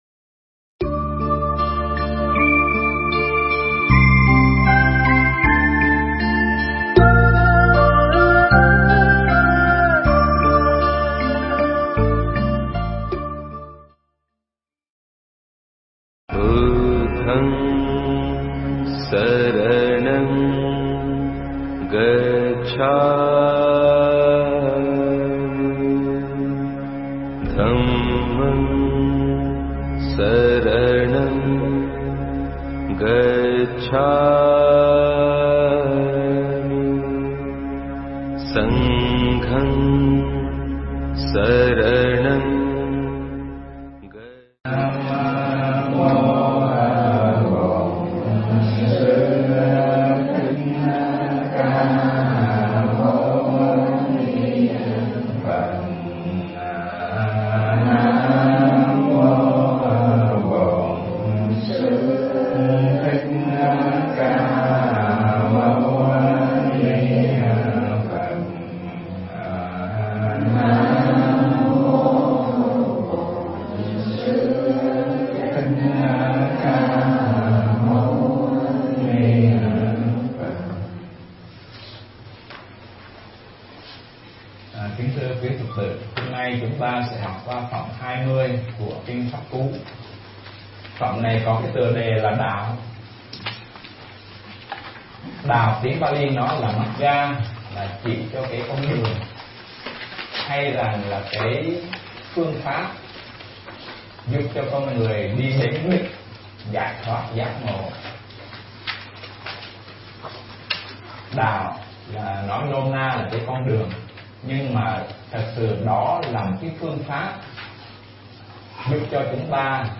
Mp3 Thuyết pháp Kinh Pháp Cú Phẩm Đạo